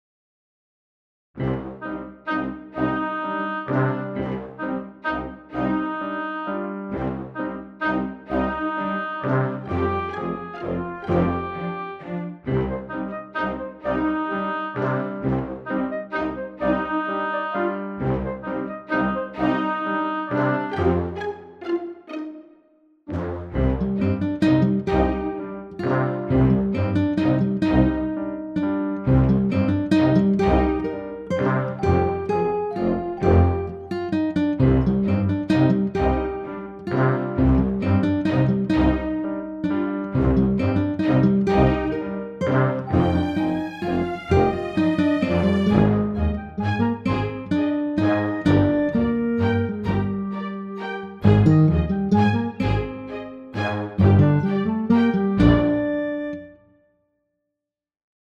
Kurz rausgerendert. Ziemlich leise und nur der 1. Part.
Ist nur nen Hall auf der Summe und noch im Kompositionsstadium.